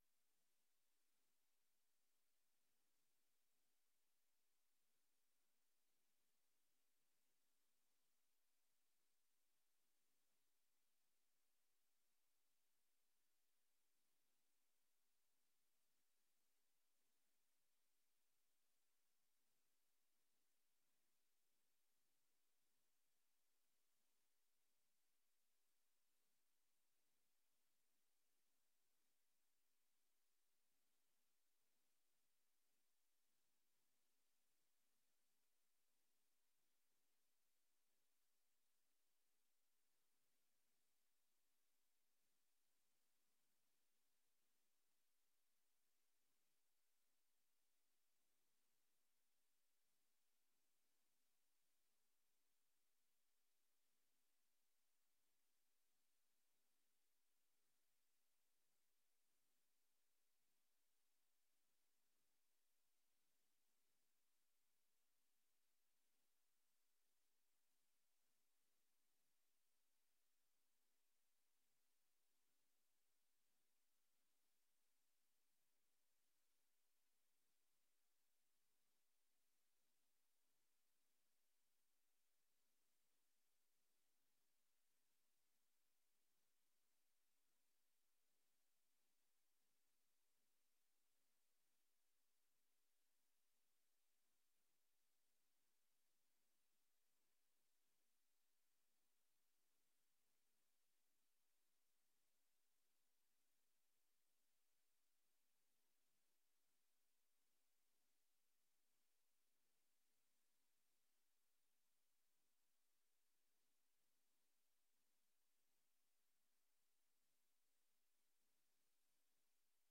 Beeldvormende vergadering 15 mei 2025 19:30:00, Gemeente Dronten